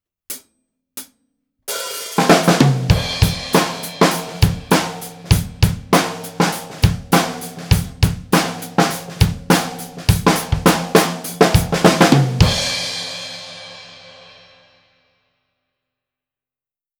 すべて、EQはしていません。
②　ドラム目の前
続いて、ドラムの目の前にマイキングしました。
高さはタムより少し高い位置です。
先ほどより、タイコ類がはっきりしてきましたね！